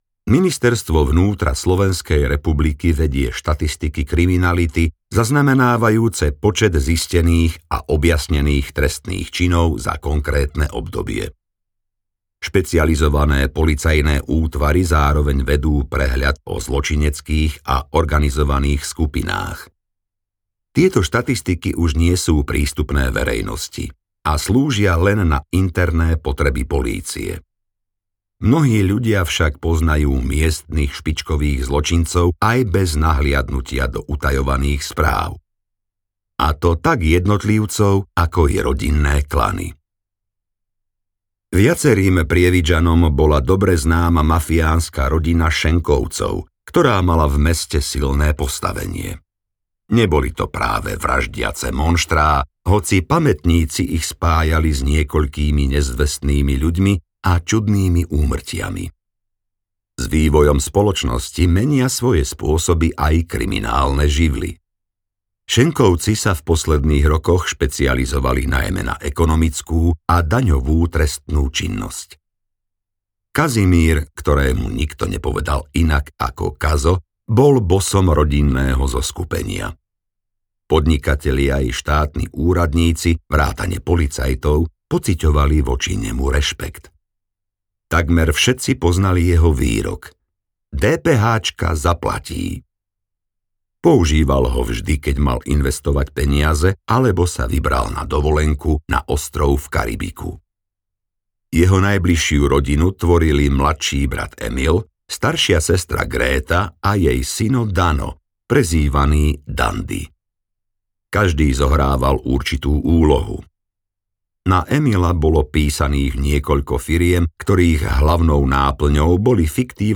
Spolok Judášov audiokniha
Ukázka z knihy
spolok-judasov-audiokniha